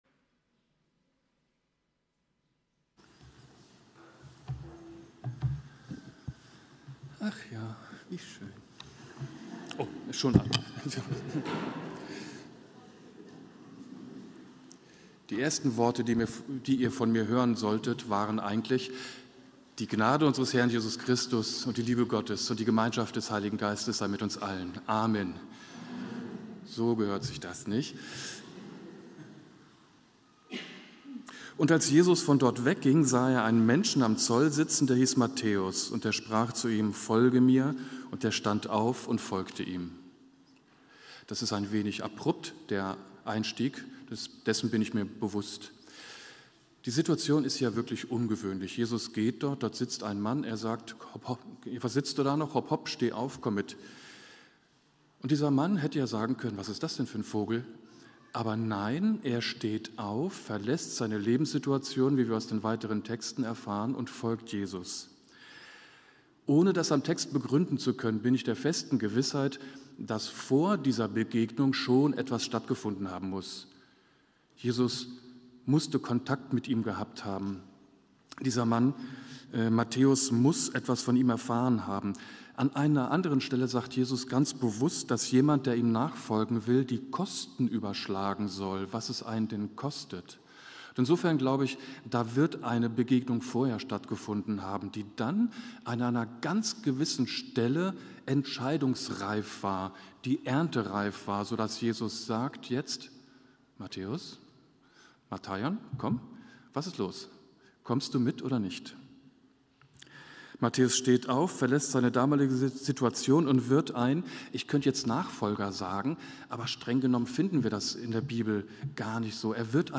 Predigt-am-11.05-online-audio-converter.com_.mp3